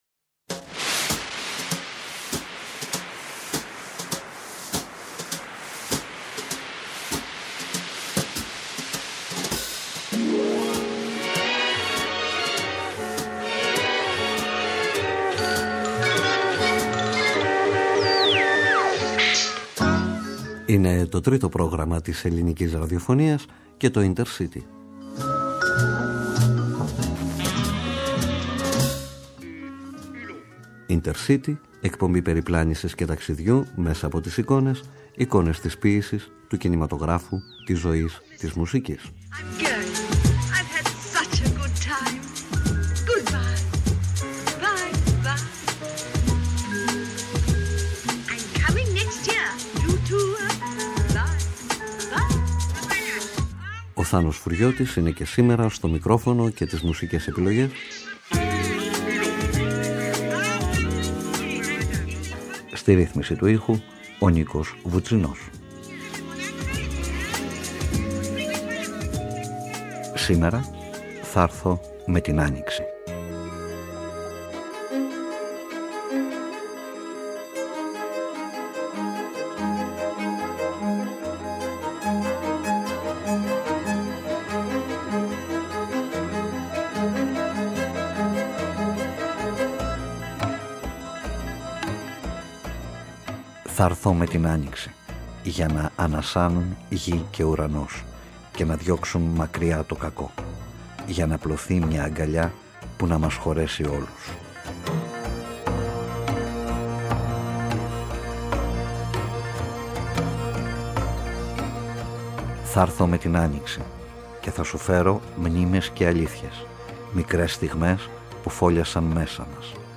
Μια πορεία αντίστροφη στο Χρόνο που σημαδεύει και σημαδεύεται από τραγούδια, εικόνες, λέξεις , αλήθειες ..
Με αφορμή ένα θέμα, μια σκέψη, ένα πρόσωπο, ένα βιβλίο, μια ταινία, ένα γεγονός ανακατεύουμε ντοκουμέντα, σελίδες βιβλίων, κείμενα, ήχους, μουσική, λόγο και πάμε ταξίδι σε παρελθόν, παρόν και μέλλον .